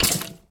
Sound / Minecraft / mob / skeleton / hurt2.ogg
hurt2.ogg